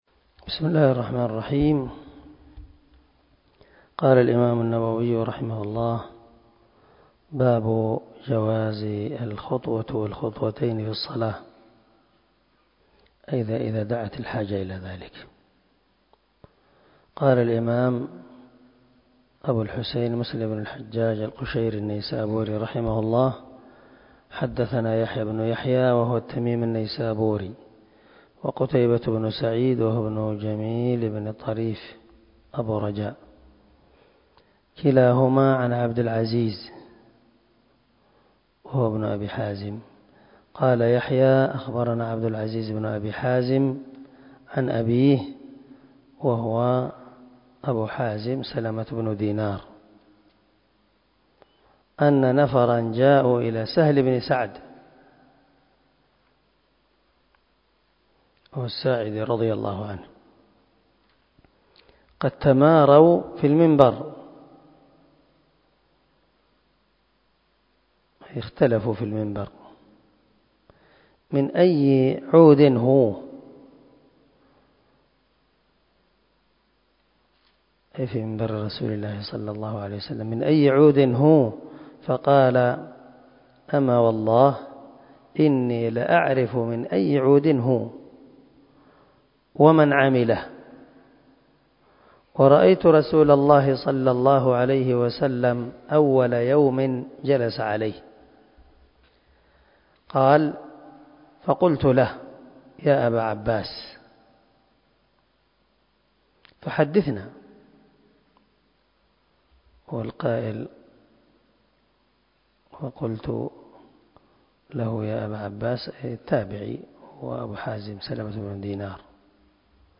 344الدرس 16 من شرح كتاب المساجد ومواضع الصلاة حديث رقم ( 544 ) من صحيح مسلم